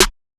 Snare (way back).wav